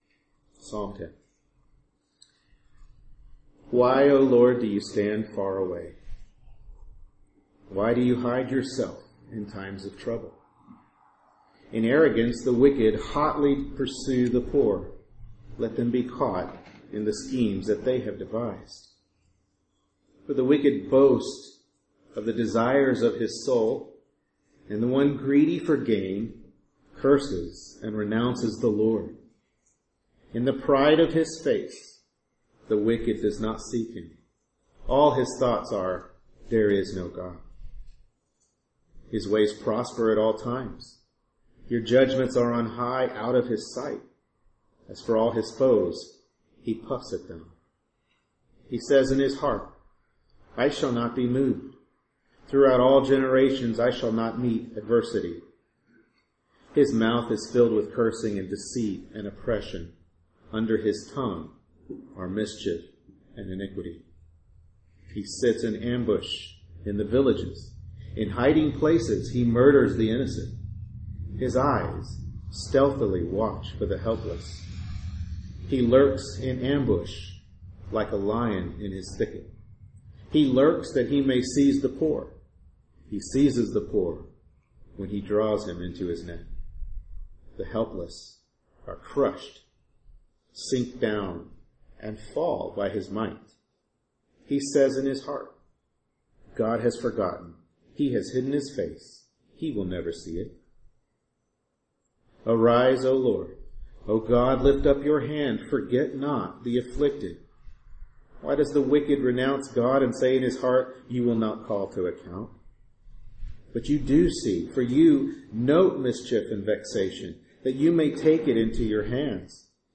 Psalm 10 Service Type: Morning Worship Service Bible Text